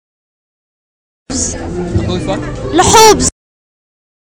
uitspraak lhoobs